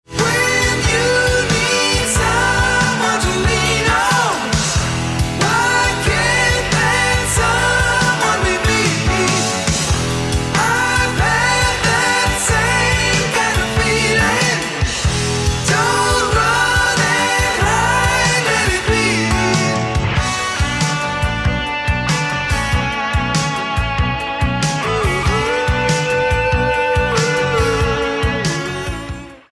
guitar, drums, keyboards, vocals
bass